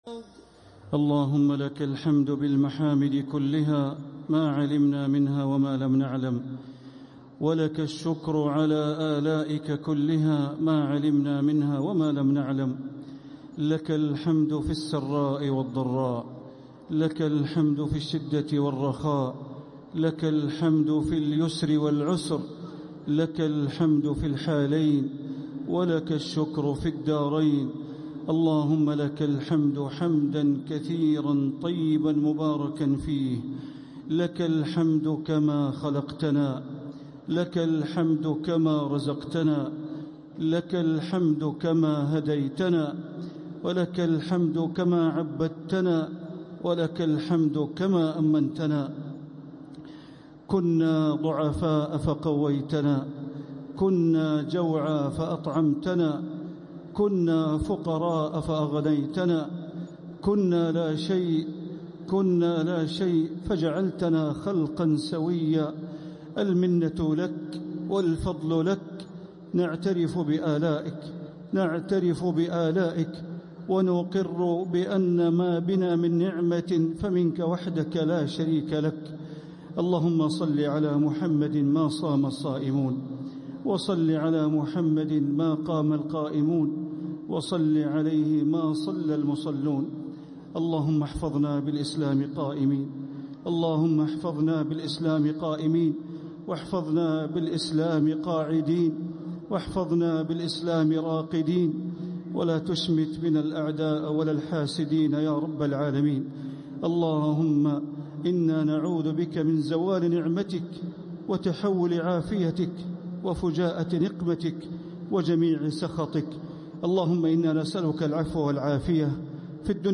دعاء القنوت ليلة 20 رمضان 1447هـ | Dua 20th night Ramadan 1447H > تراويح الحرم المكي عام 1447 🕋 > التراويح - تلاوات الحرمين